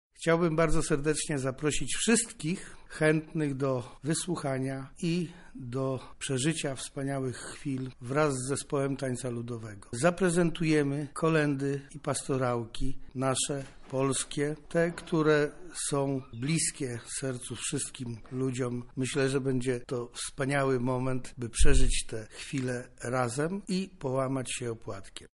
mówi